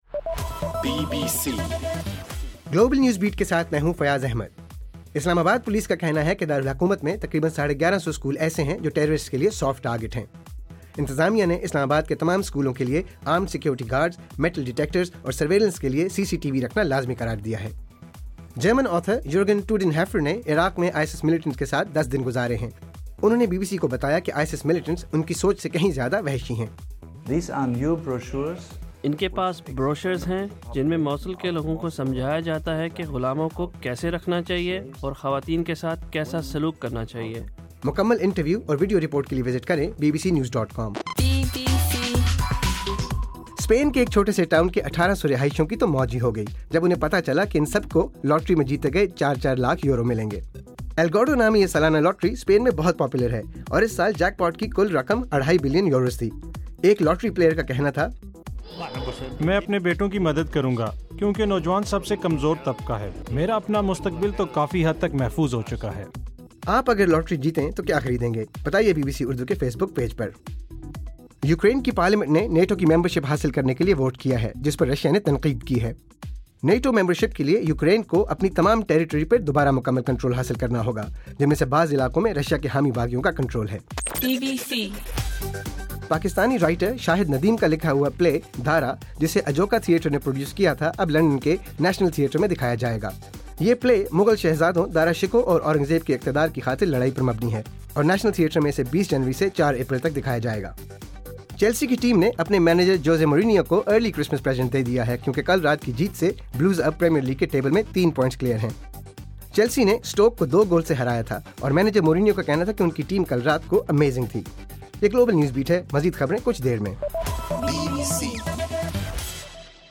دسمبر 23: رات 9 بجے کا گلوبل نیوز بیٹ بُلیٹن